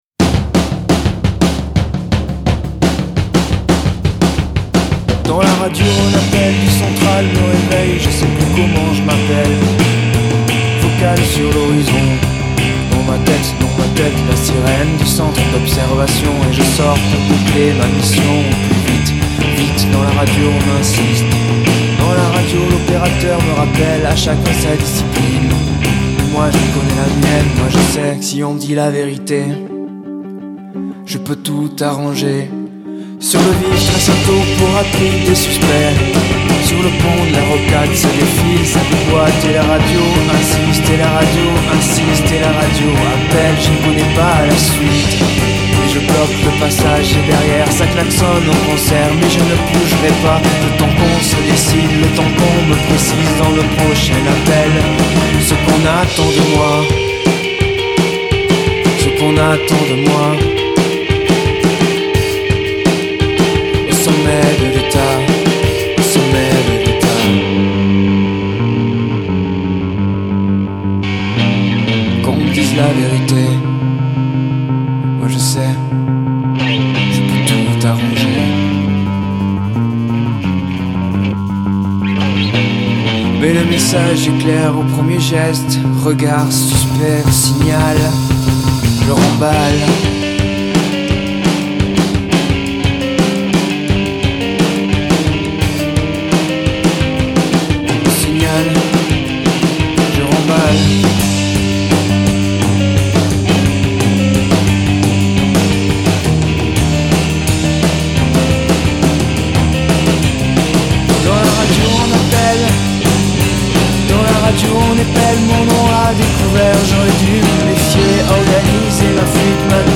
Alternatif, entêtant
Souvent simples et près de l’os